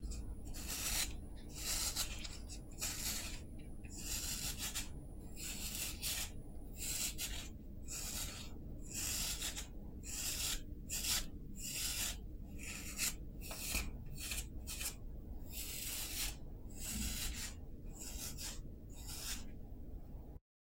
Звук забивания гвоздей в дерево Скачать звук music_note Быт , Дом , обиход save_as 312.3 Кб schedule 0:20:00 8 0 Теги: mp3 , быт , бытовые звуки , гвозди , дерево , звук , металл , молоток , стройка , стук